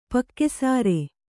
♪ pakke sāre